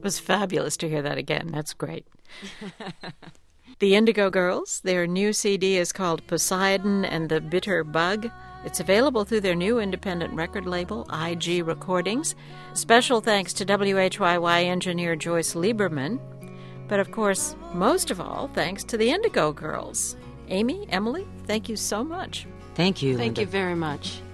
06. interview (0:24)